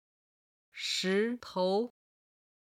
グーは「石头(shí tou)石」
※「头」は本来軽音ですが、音源の都合上二声を使っています。